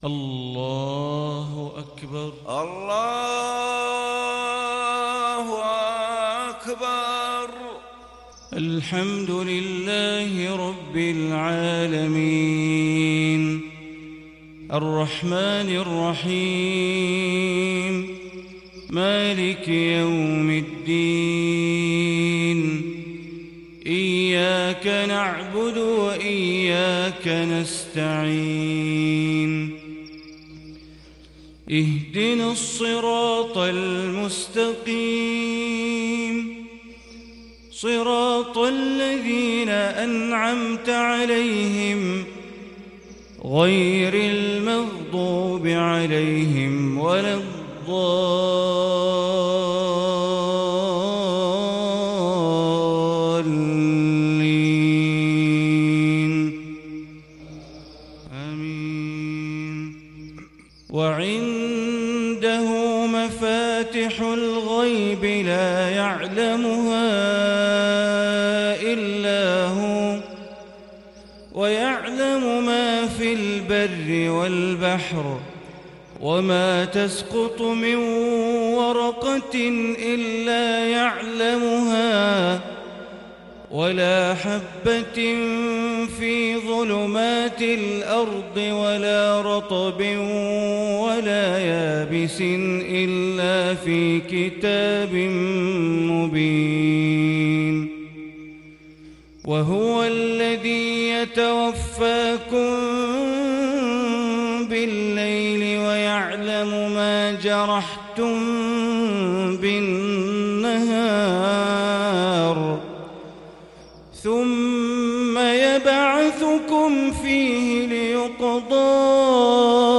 صلاة الفجر للشيخ بندر بليلة 16 رجب 1441 هـ
تِلَاوَات الْحَرَمَيْن .